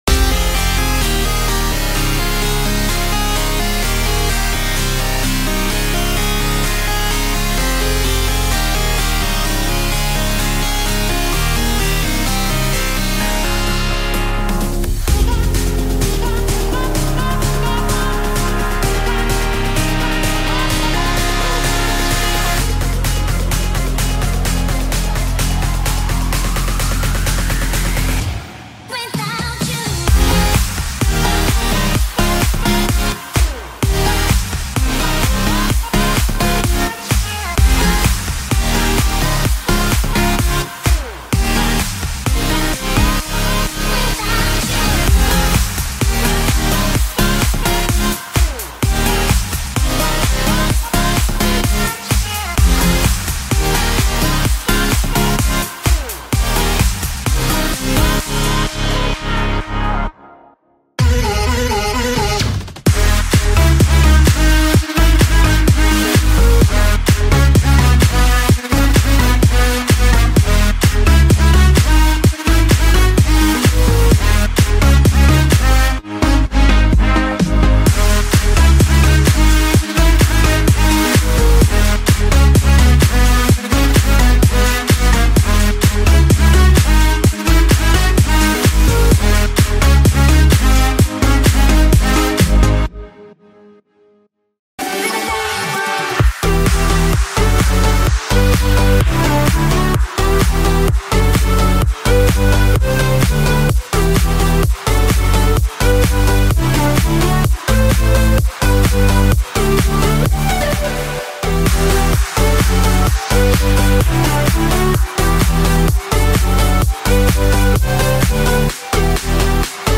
385个高品质样品和300个用于未来之家的预设！
➜ 145 One Shots (Kicks, Claps, Snares, Snaps, Hihats, Rides)
➜ 75 Drum Loops (Drop Claps, Preshifted Clap Loops, Full Drum Loops, High Loops, Buildup Drums, Drum Fills)
➜ 165 FX Sounds (Impacts, Exhausts, Risers, Sub Drops, Reverse Vocals, Crowd Noise, Brass Stabs, Ambience, Reverb Plucks, Synth Drops, Sweep Transitions)